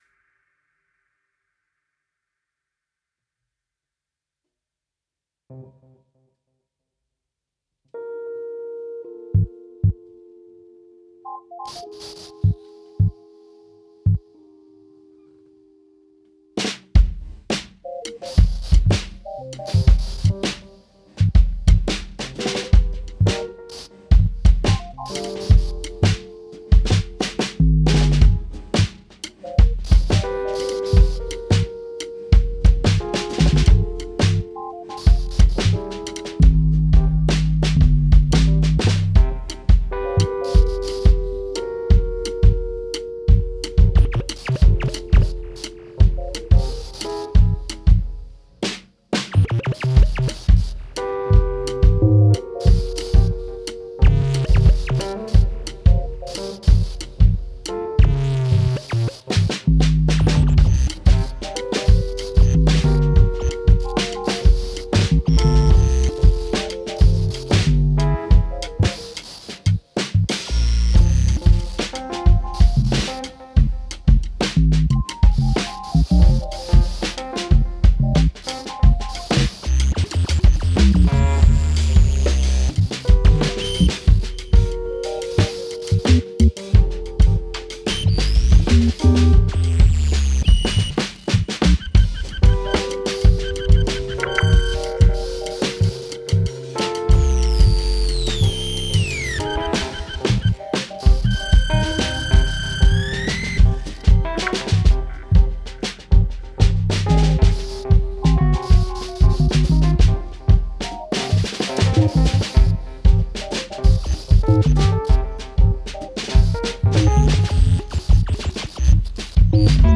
They undertook more voyages exploring noises of live synthesization
drums